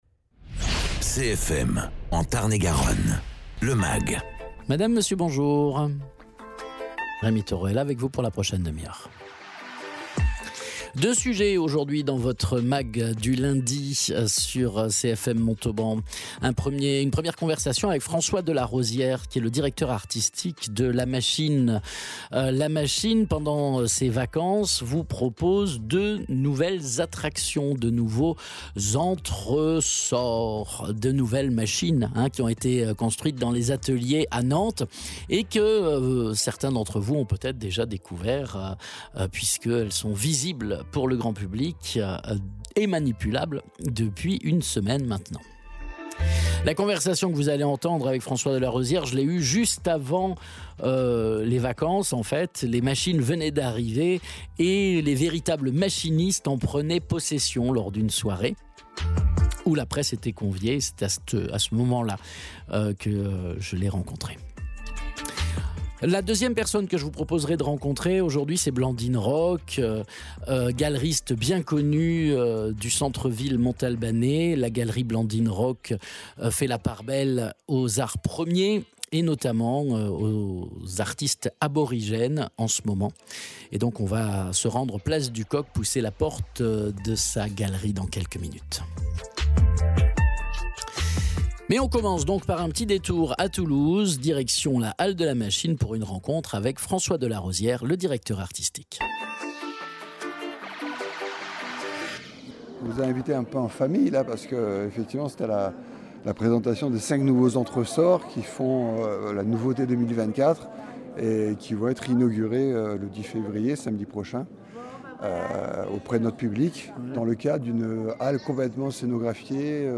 Deux entretiens aujourd’hui dans le mag